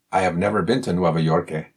Q&A_13_response_w_accent.mp3